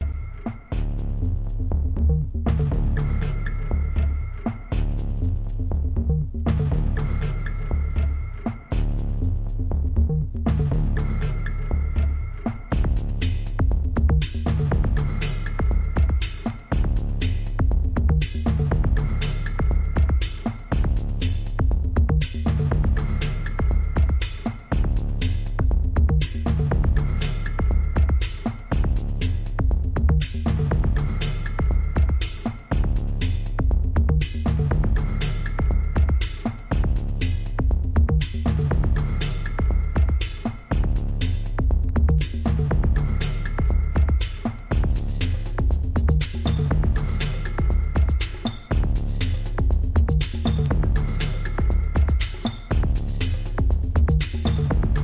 Downtempo,エレクトロニカの傑作!